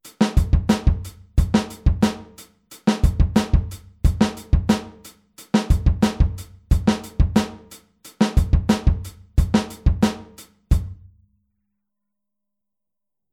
Anders als die Wechselschläge bei 16tel spielt die rechte Hand durchgängig alle 8tel.
Groove13-8tel.mp3